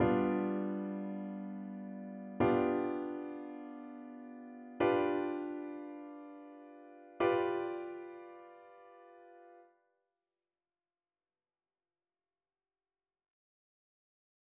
딸림7화음의 전위 (오디오)